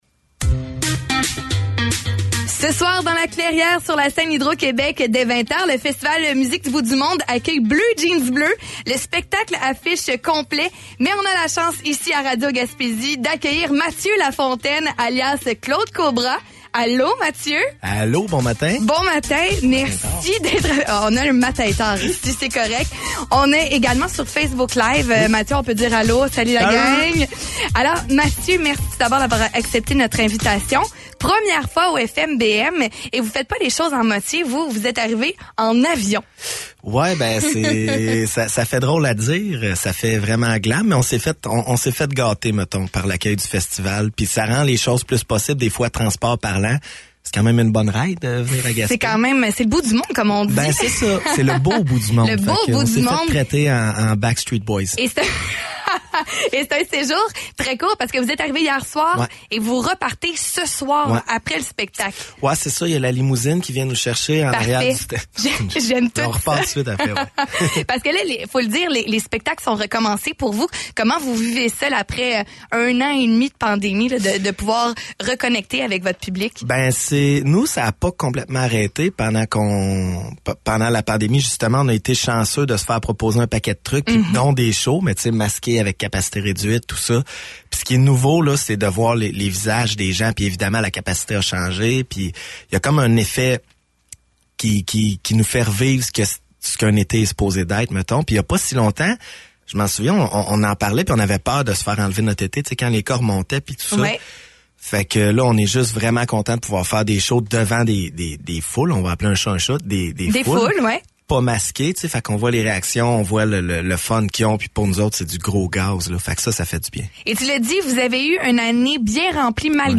entrevueBleujeansbleu.mp3